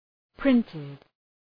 {‘prıntıd}